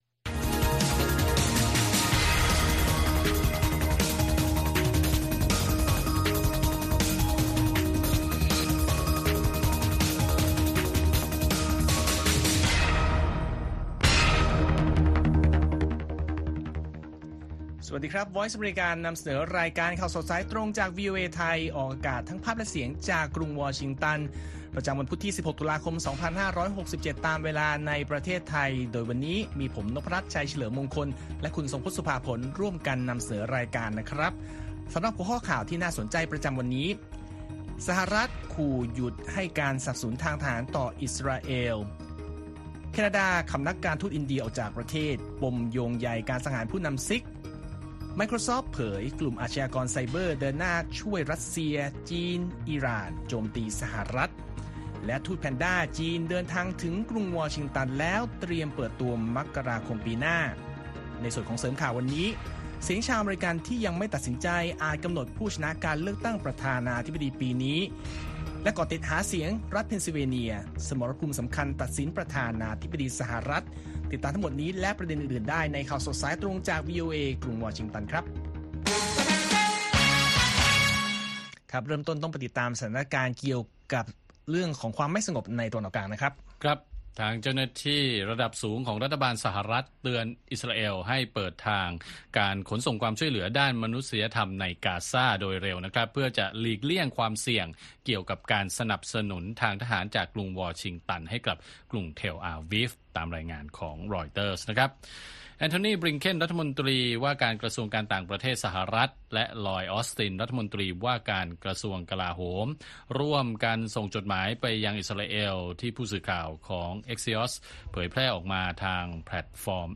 ข่าวสดสายตรงจากวีโอเอ ไทย พุธ ที่ 16 ตุลาคม พ.ศ. 2567